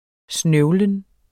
Udtale [ ˈsnœwlən ]